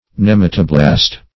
Search Result for " nematoblast" : The Collaborative International Dictionary of English v.0.48: nematoblast \nem"a*to*blast\, n. [Nemato- + -blast.]